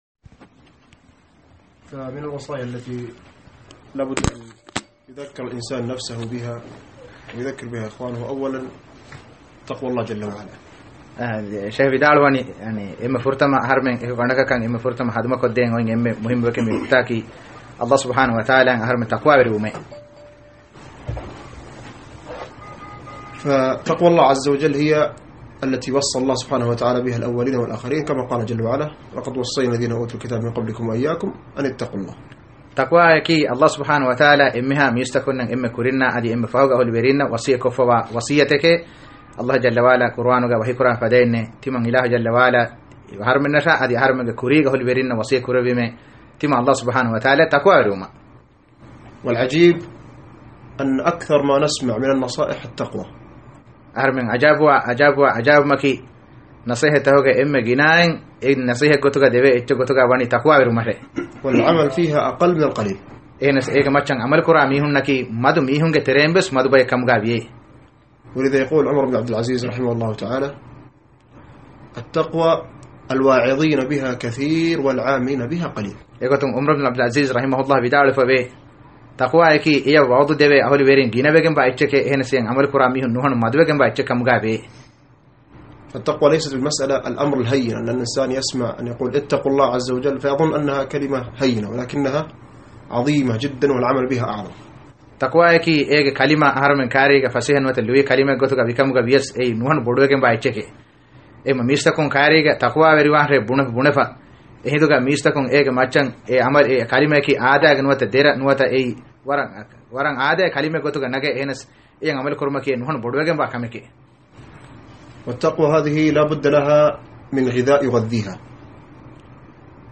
2016 މާރޗް ގައި ކުރި ޢުމްރާ ދަތުރުގައި މަކްތަބާ ސަލަފިއްޔާގެ އިޚްވާނުން ސަޢުދި ޢަރަބިއްޔާގެ ސަލަފީ މަޝައިޚުން އަދި ކިޔަވާ ދަރިވަރުންނާއިއެކު ބޭއްވި މަޖްލިސްތަކުގެ ރެކޯޑިން: